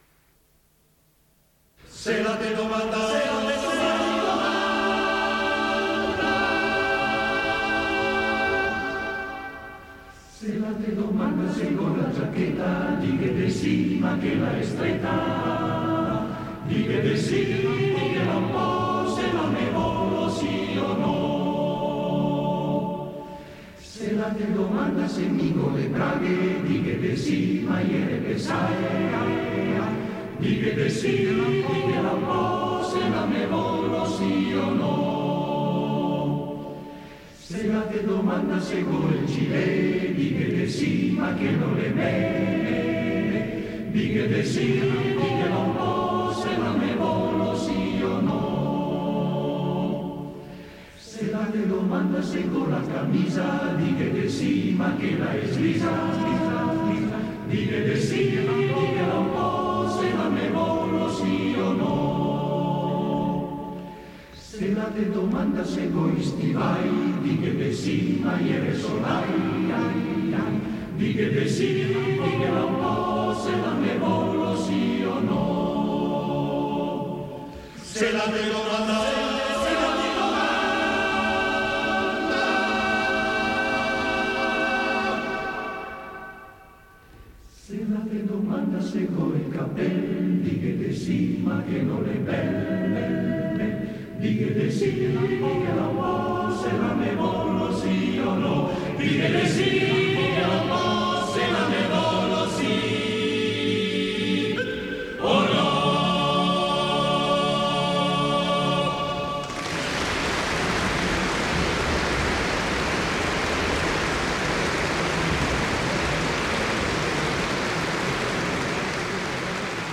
Arrangiatore: Bettinelli, Bruno
Esecutore: Coro CAI Uget